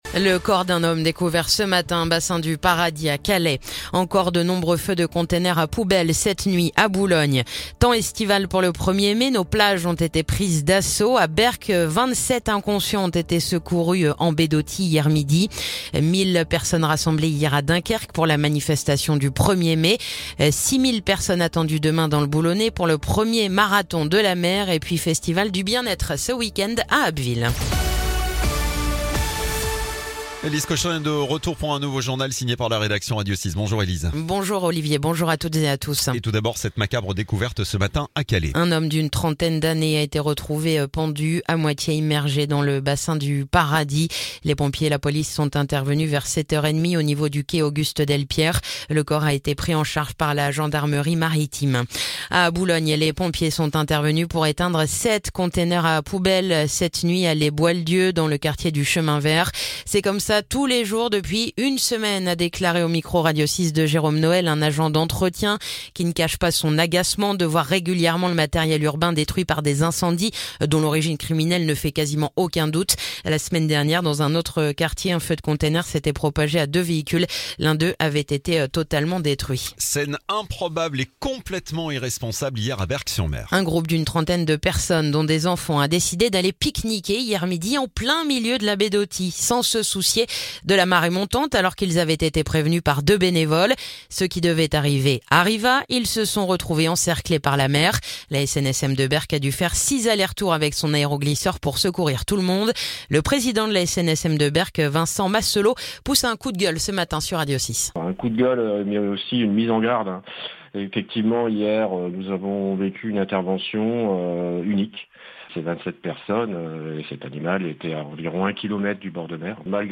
Le journal du vendredi 2 mai